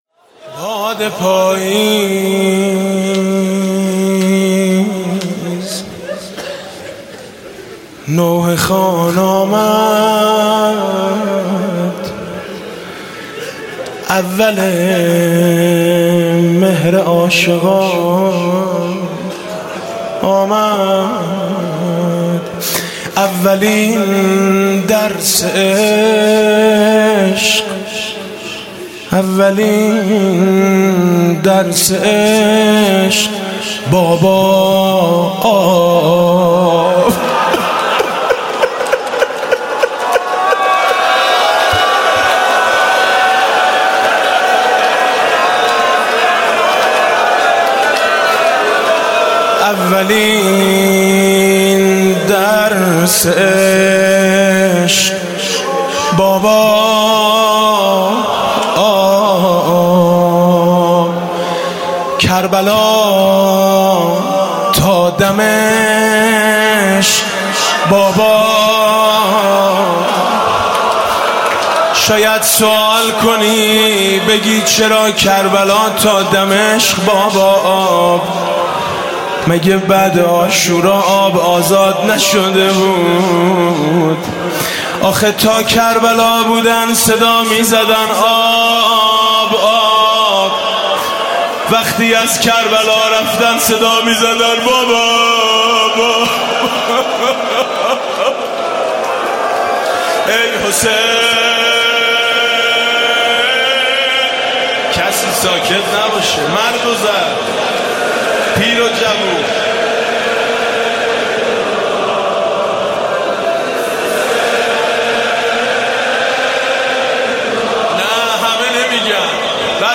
شب اول محرم 96 - هیئت میثاق - روضه - اول مهر عاشقان آمد